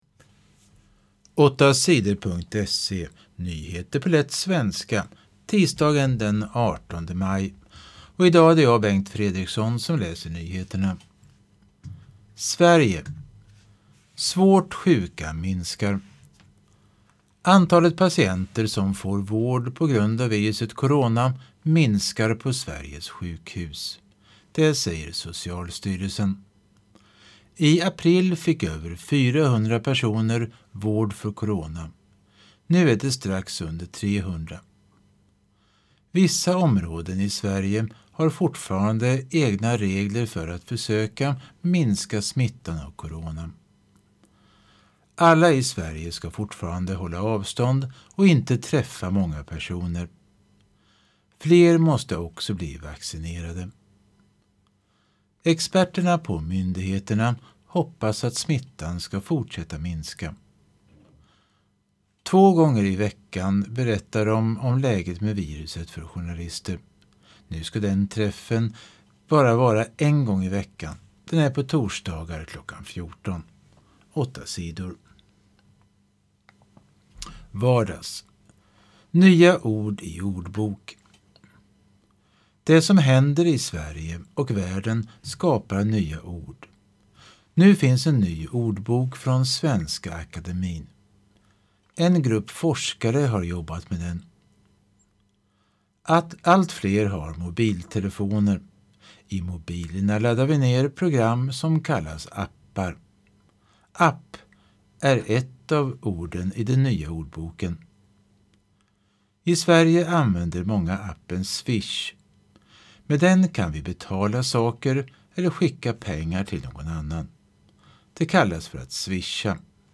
Nyheter på lätt svenska den 18 maj